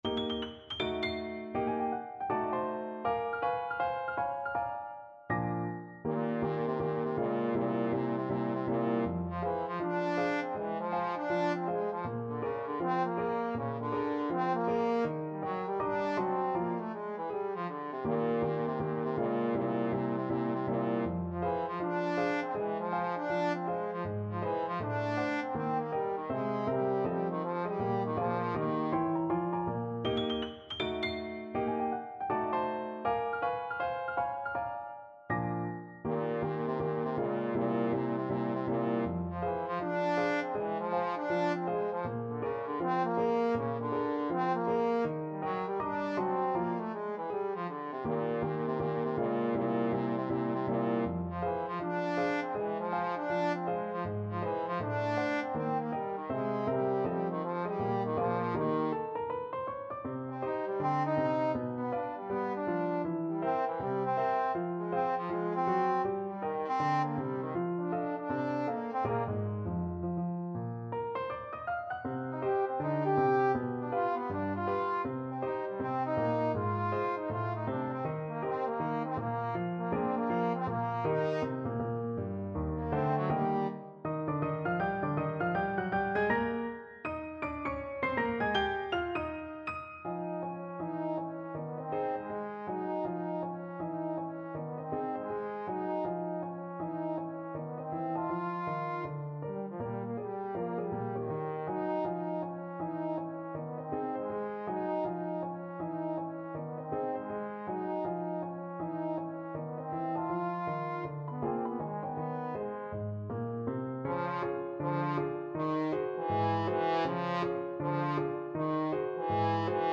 = 80 Swung
2/2 (View more 2/2 Music)
Bb3-G5
Jazz (View more Jazz Trombone Music)